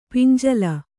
♪ pinjala